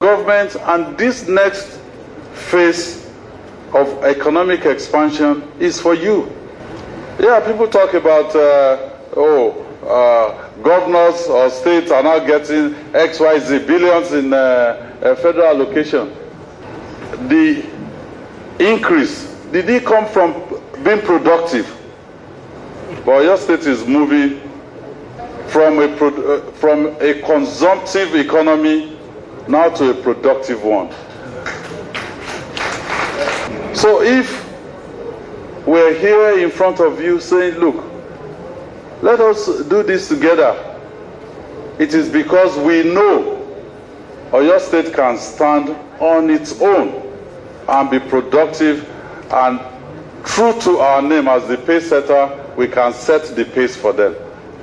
While speaking at a gathering in Ibadan, the governor asked whether the rise in allocations was driven by the country becoming more productive.